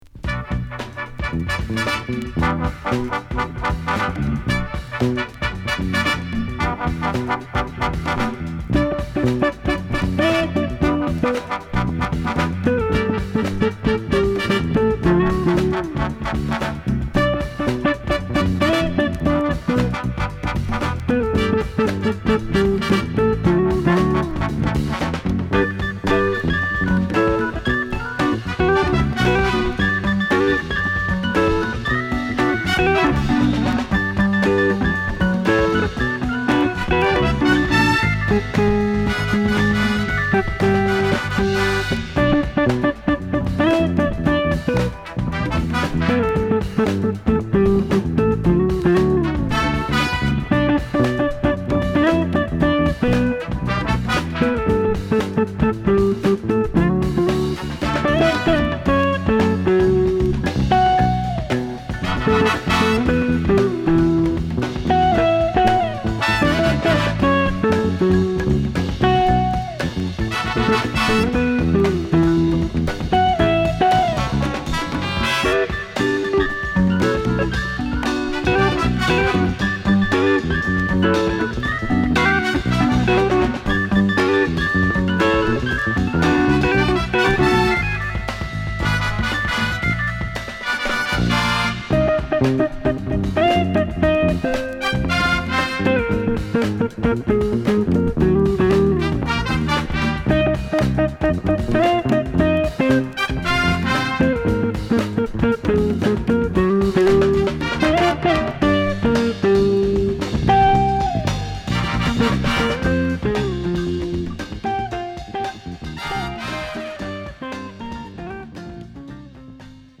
曲としても印象的なホーンフレーズから気持ち良く疾走するナイスカヴァー！